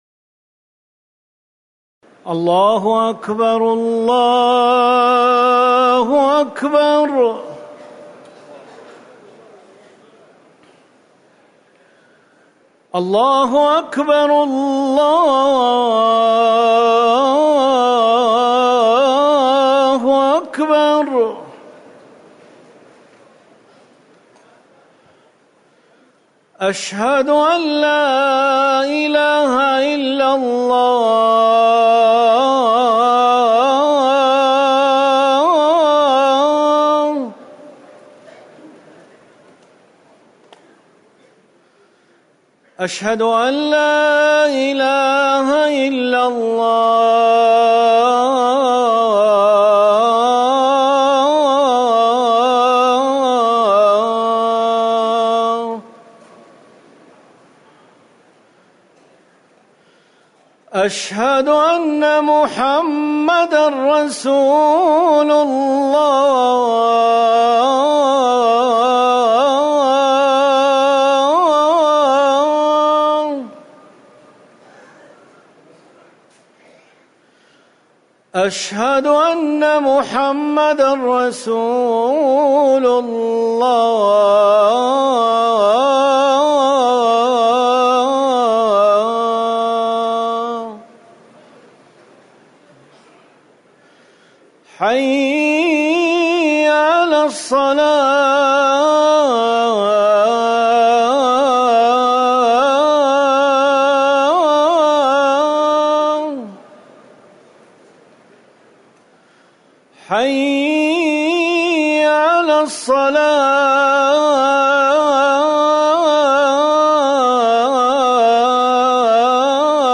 أذان المغرب
تاريخ النشر ٩ محرم ١٤٤١ هـ المكان: المسجد النبوي الشيخ